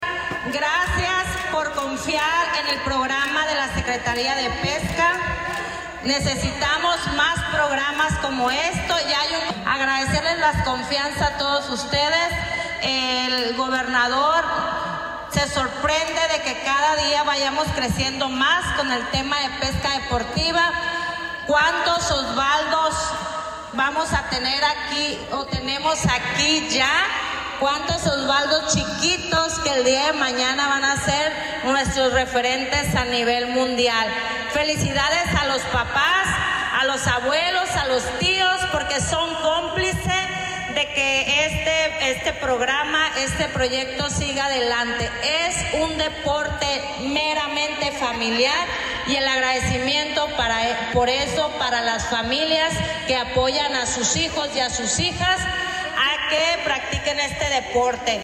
Durante el evento, la titular de la Secretaría de Pesca y Acuacultura del Gobierno de Sinaloa, Flor Emilia Guerra Mena, expresó su agradecimiento a las familias por la confianza y por ser parte de este programa que llega a las y los pequeños que representan el futuro de la pesca deportiva en el estado: